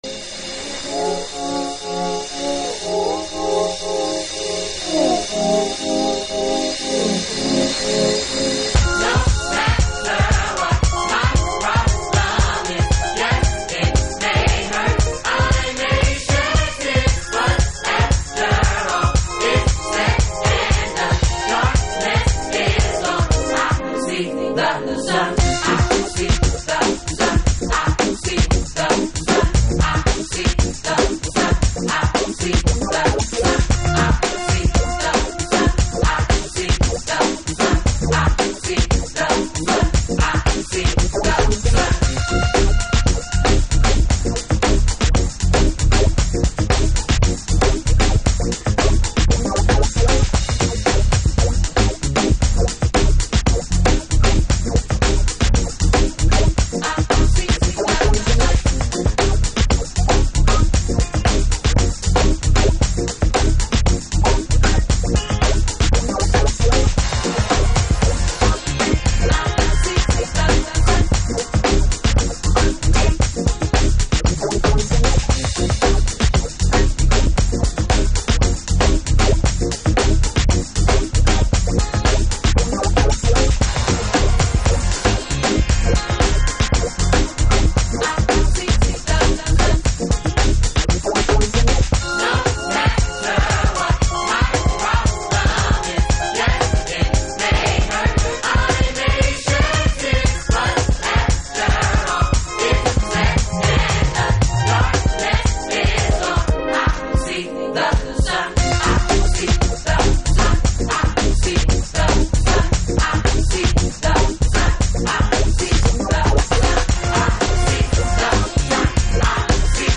Future Jazz / Broken beats